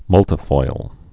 (mŭltə-foil)